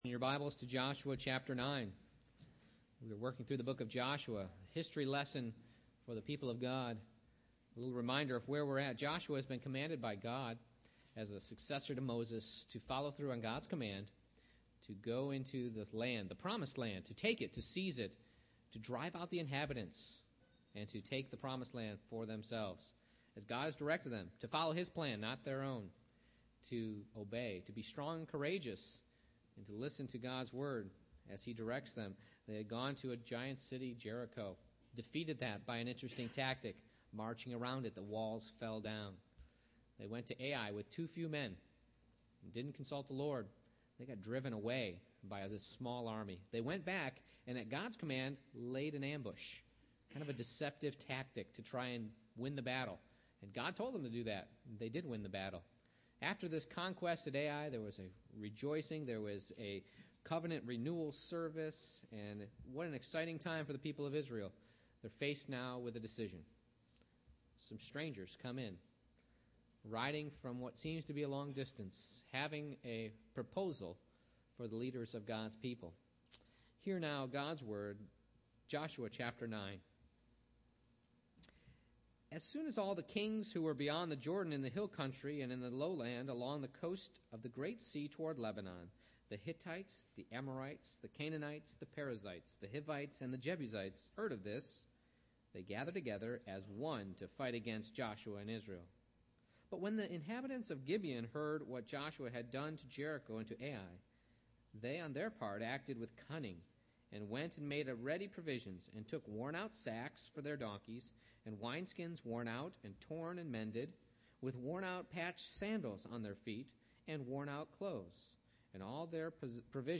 Joshua 9:1-27 Service Type: Morning Worship I. Reaction to the Conquest A. United Opposition B. Deceptive Cowards II.